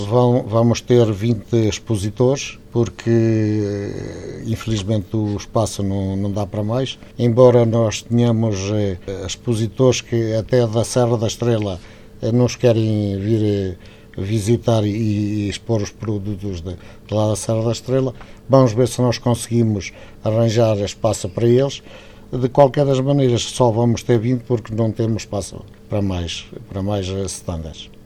O seu presidente, que está em fim de mandato atesta que gostaria de ter mais expositores mas não tem espaço para mais: